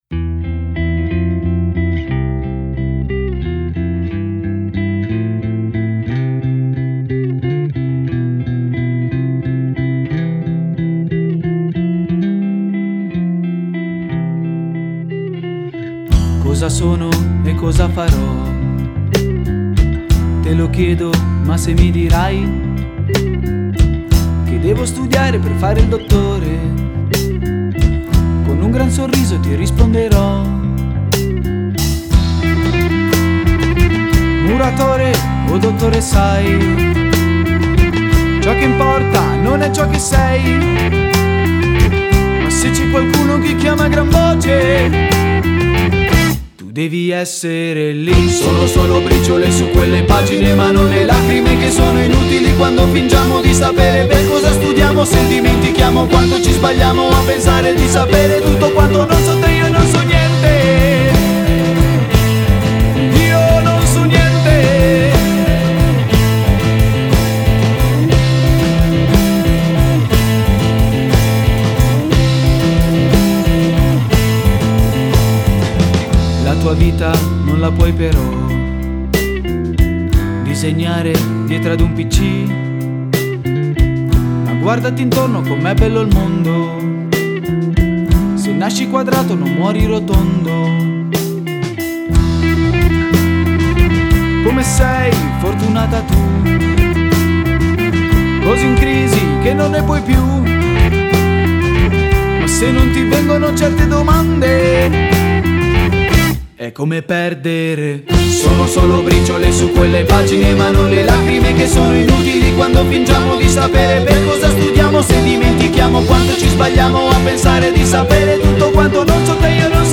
Ballad Pop